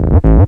tekTTE63025acid-A.wav